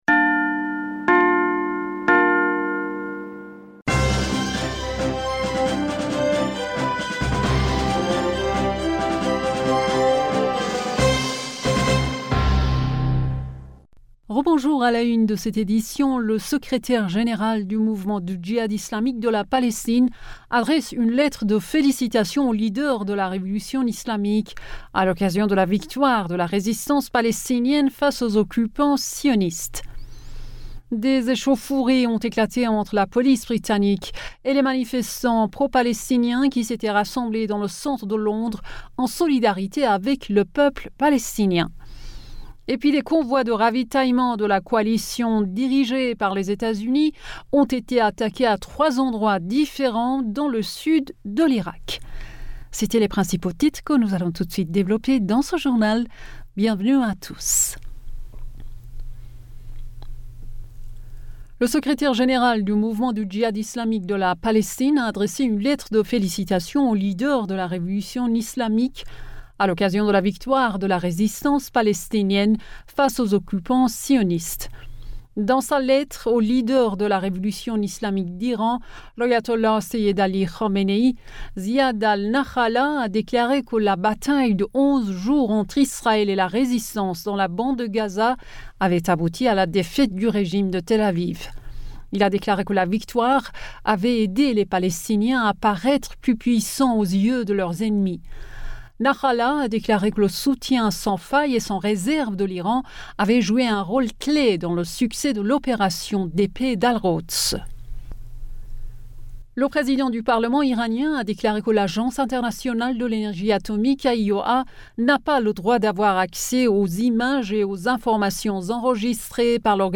Bulletin d'information du 23 mai 2021